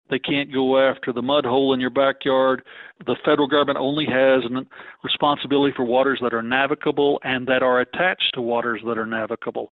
CLICK HERE to listen to commentary from Congressman Frank Lucas.